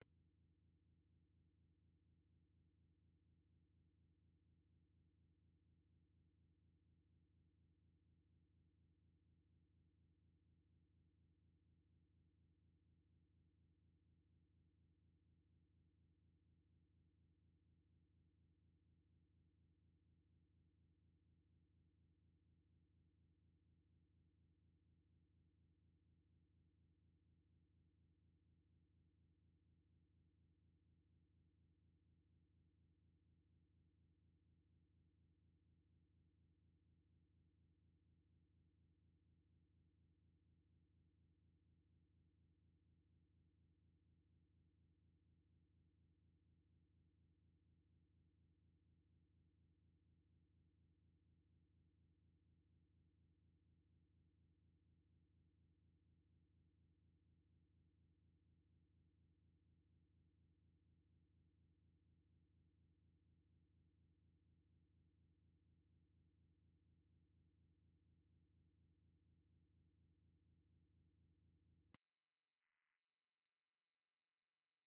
音声は入れてありません。